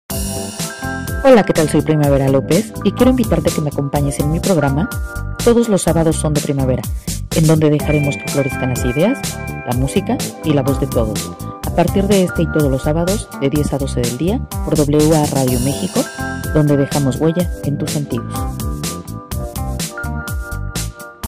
TEMÁTICA. Versátil, divertido y alegre.